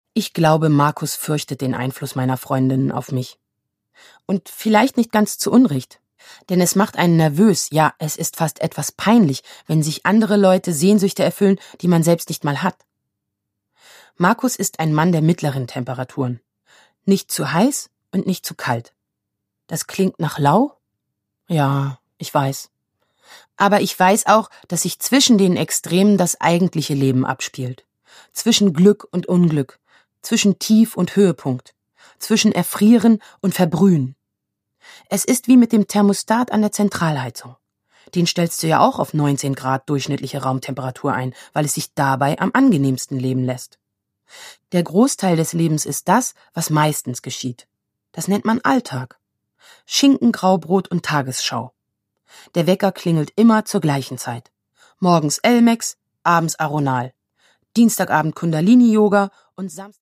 Produkttyp: Hörbuch-Download
Gelesen von: Anneke Kim Sarnau